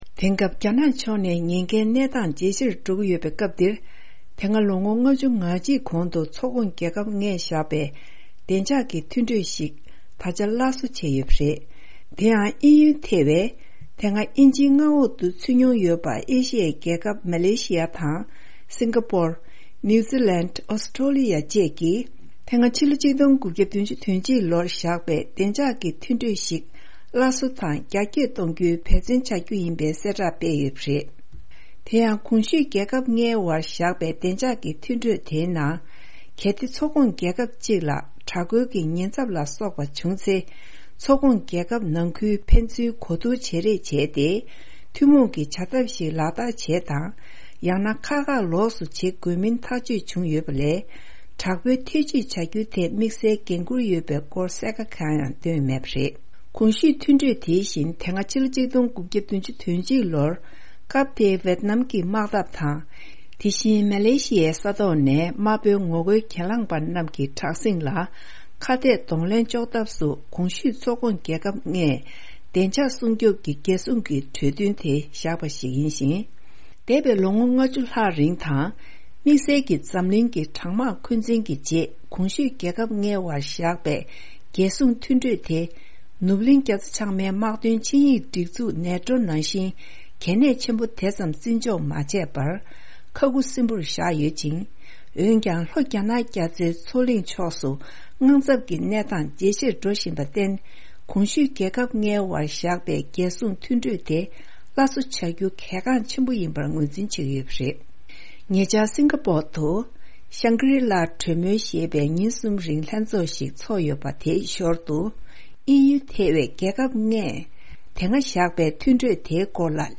ཕབ་སྒྱུར་དང་སྙན་སྒྲོན་ཞུ་རྒྱུ་རེད།